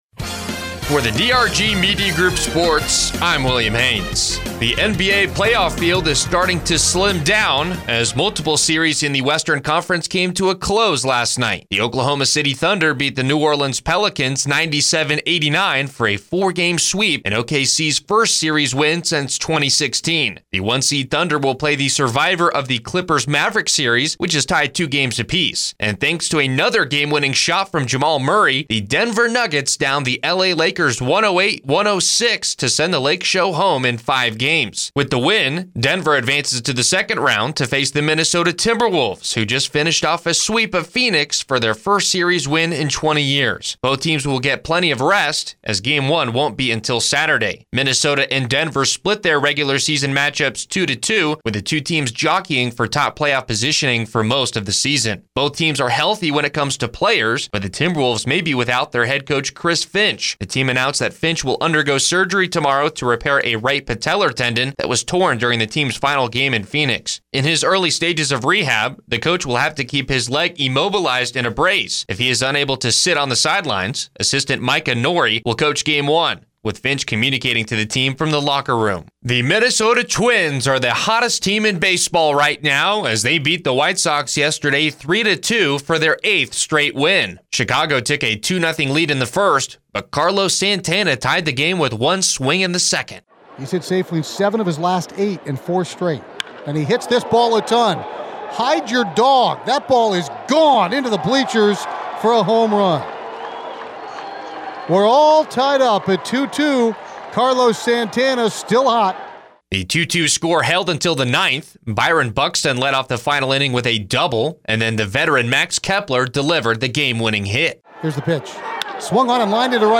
sports news update
4-30-24-midday-sports.mp3